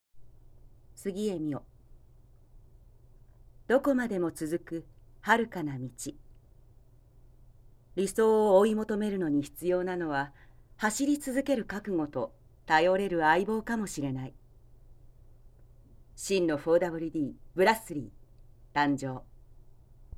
ボイス